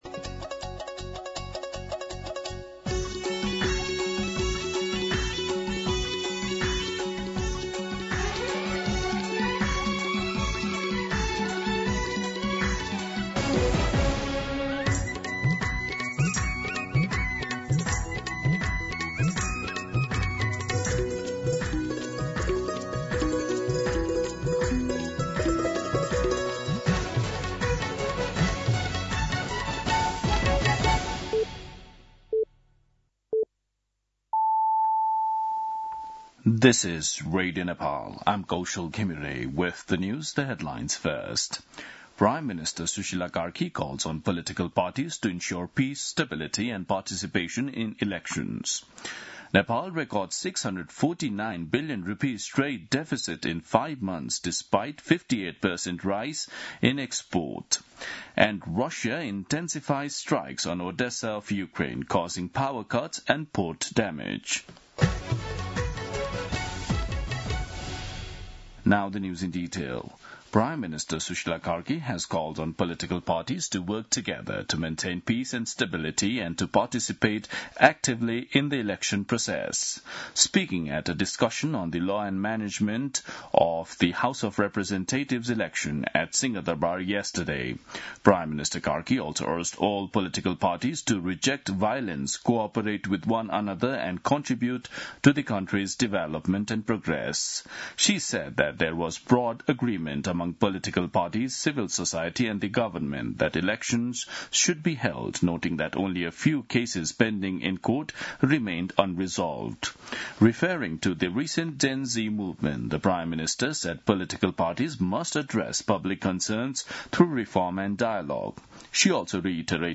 दिउँसो २ बजेको अङ्ग्रेजी समाचार : ८ पुष , २०८२